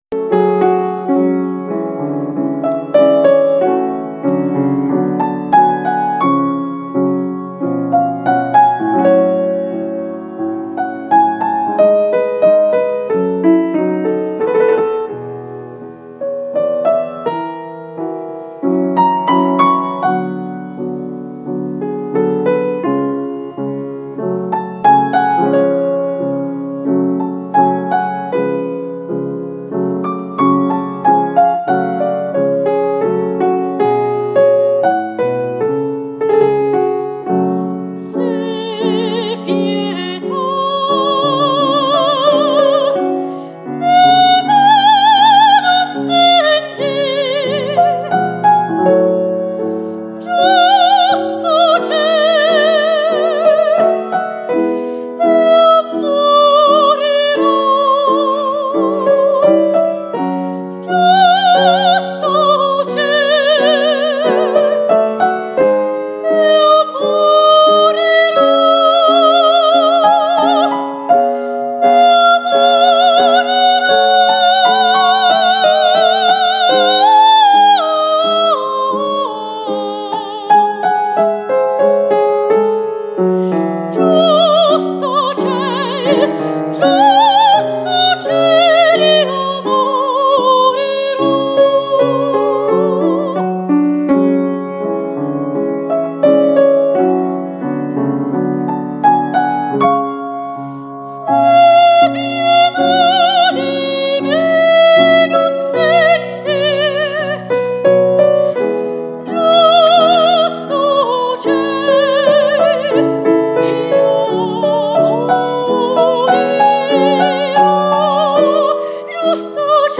Pervin Çakar dengbêja opera ya Kurd, bi dengê xwe yê zelal û bilind karîbû cihê xwe di nav hunermend û dengbêjên mezin yên muzîka klasîk bigire